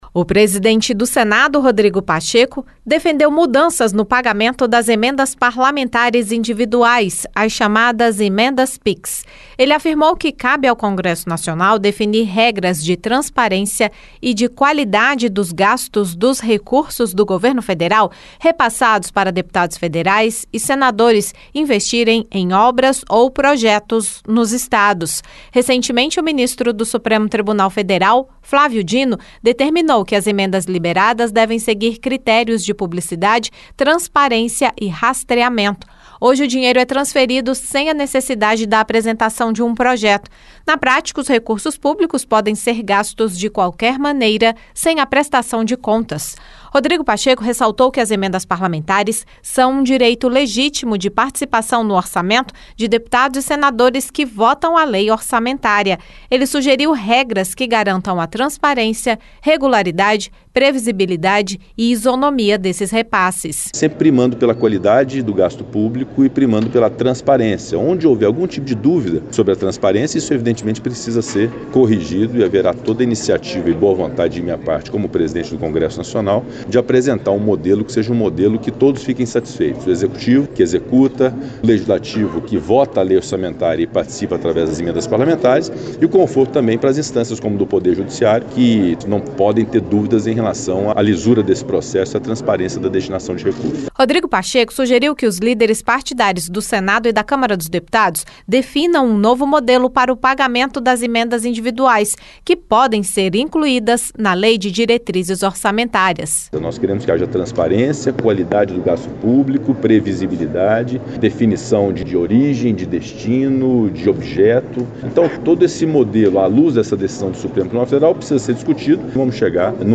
Durante entrevista coletiva nesta terça-feira (13), o presidente do Senado, Rodrigo Pacheco, defendeu aperfeiçoamentos nas emendas orçamentárias individuais do tipo transferência especial — conhecidas como emendas pix.